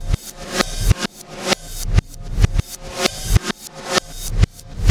Black Hole Beat 21.wav